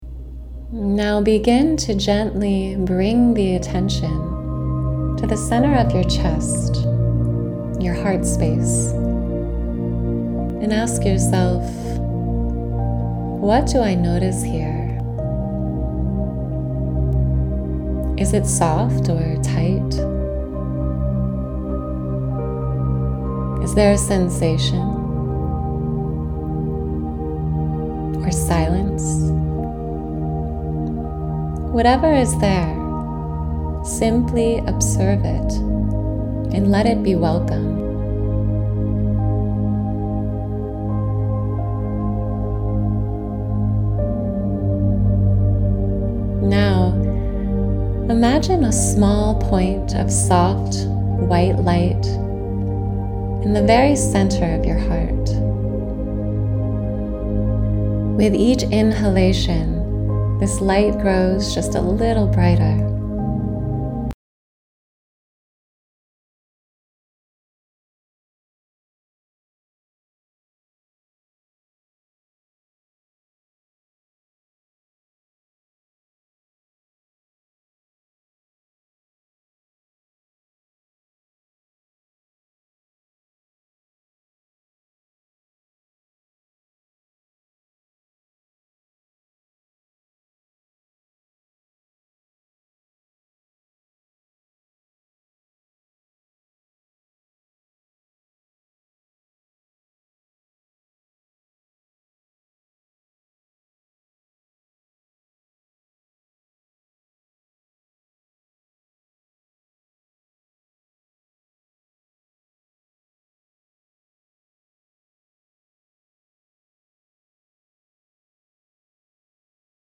This 14-minute meditation gently guides you back to the heart's natural openness, clarity and compassion. Through grounding, breath, and heart-centered visualization, you'll soften tension, release protective patterns, and reconnect with the spacious warmth of love and inner truth.